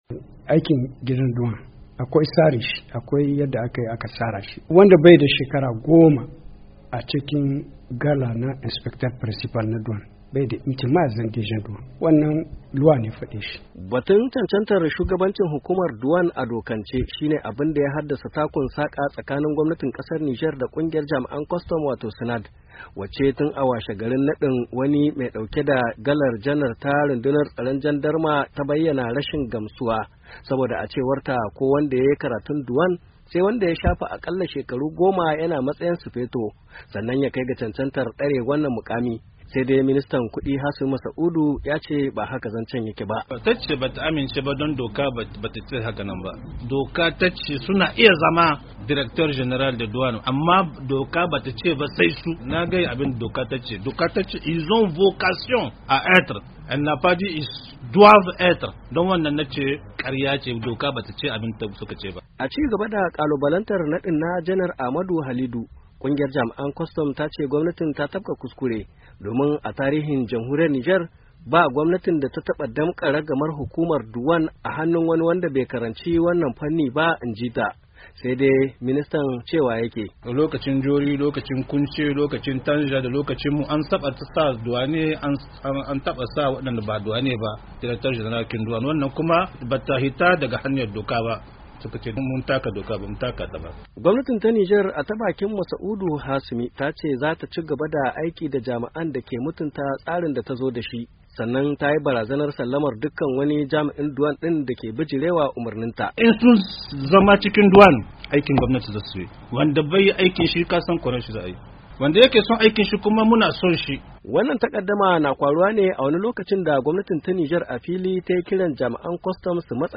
WASHINGTON, DC —